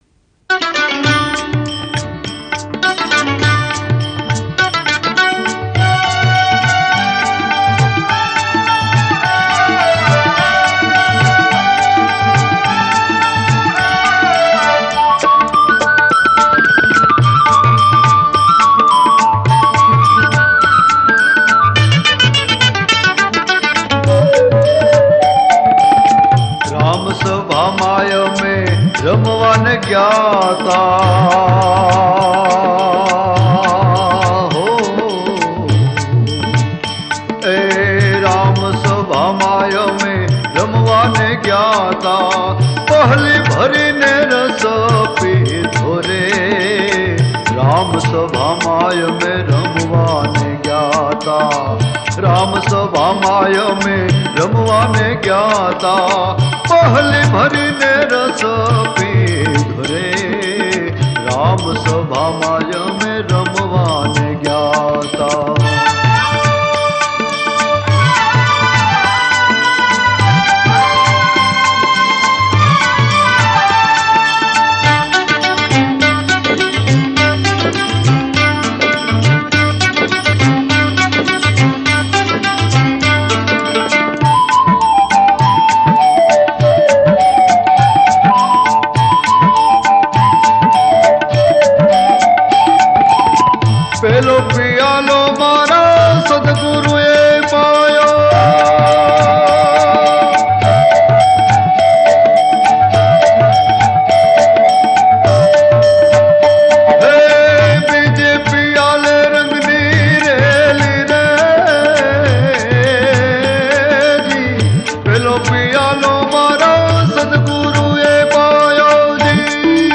ગીત સંગીત ભજનાવલી - Bhajans